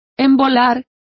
Also find out how embolo is pronounced correctly.